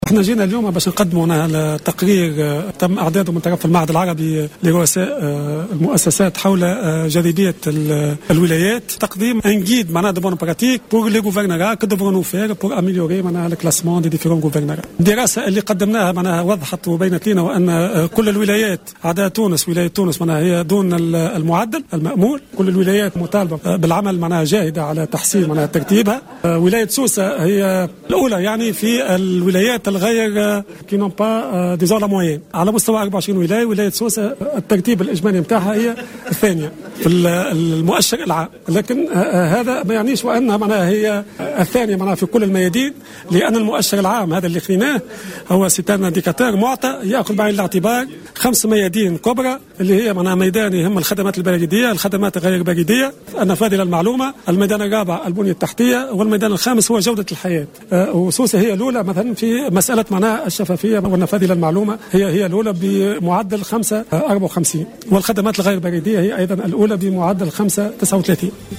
على هامش الندوة الوطنية التي انتظمت بسوسة اليوم